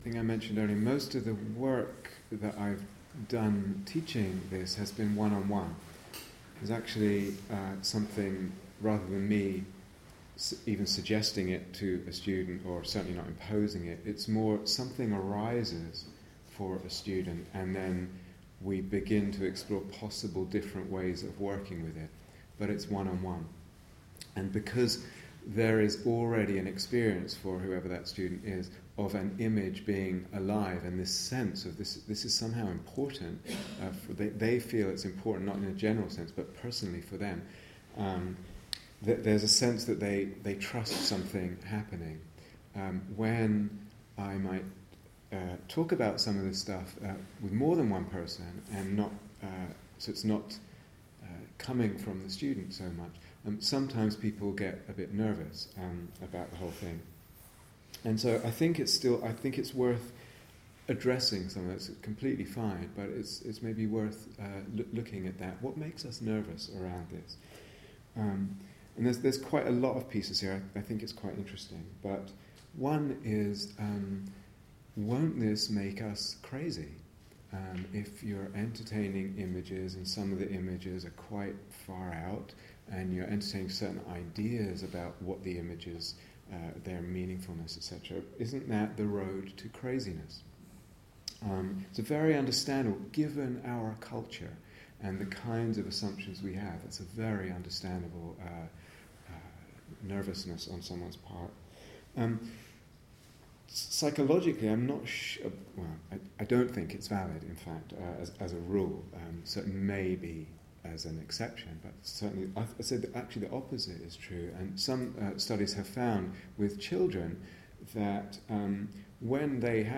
Day Retreat, Bodhi Tree Brighton